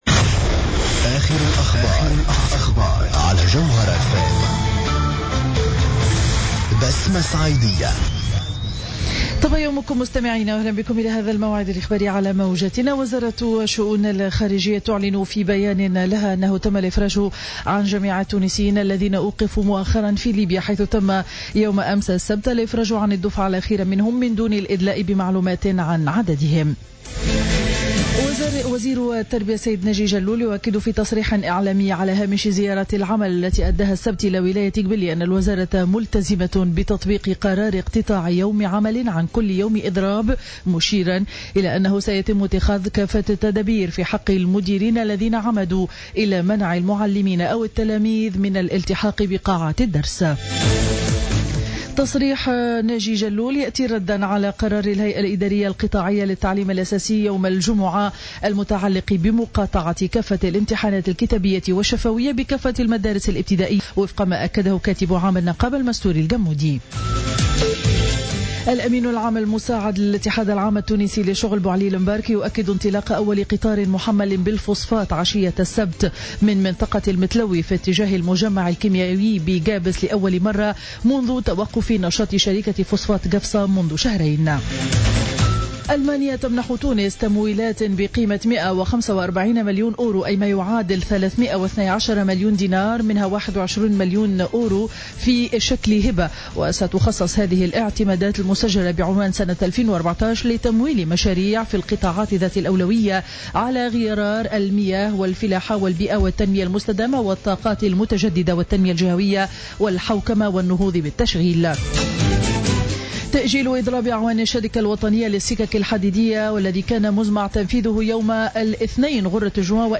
نشرة أخبار السابعة صباحا ليوم الأحد 31 ماي 2015